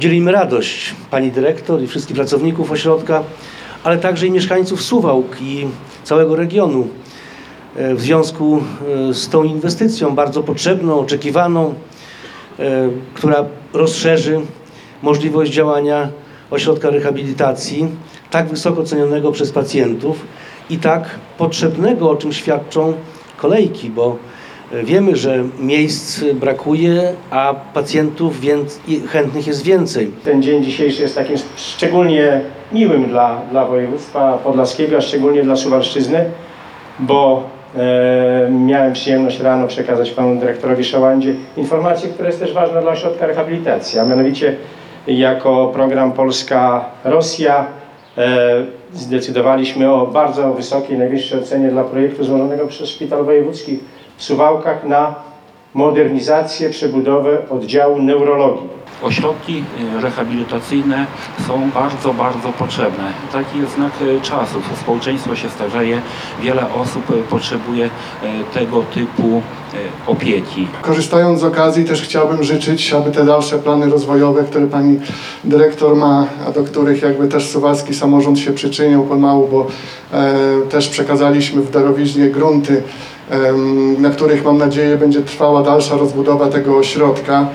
Podczas uroczystego otwarcia inwestycji gratulowali: Jarosław Zieliński, poseł i wiceminister MSWiA, Marek Malinowski, członek zarządu województwa podlaskiego, Cezary Cieślukowski, radny wojewódzki i Łukasz Kurzyna, zastępca prezydenta Suwałk.